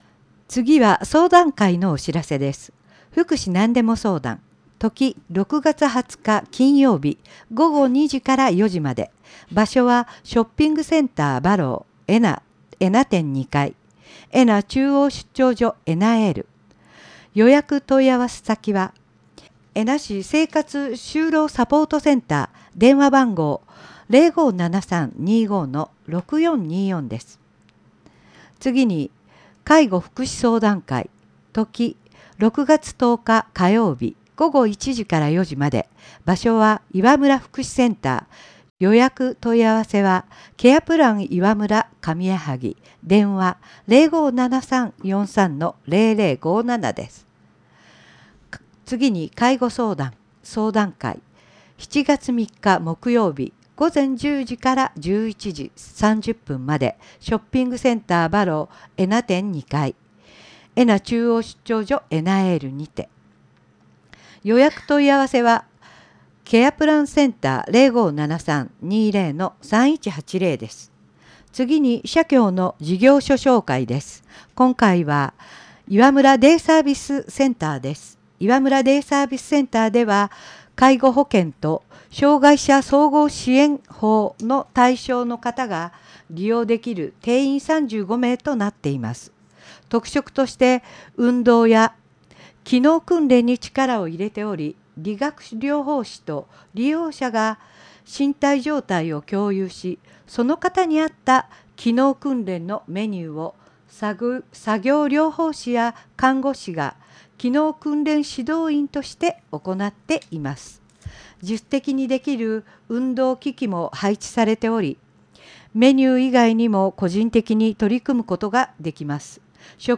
広報紙音声版はこちら↓